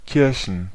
Ääntäminen
Ääntäminen Tuntematon aksentti: IPA: /ˈkɪʁçn/ IPA: /ˈkɪʁ.çən/ Haettu sana löytyi näillä lähdekielillä: saksa Käännöksiä ei löytynyt valitulle kohdekielelle. Kirchen on sanan Kirche monikko.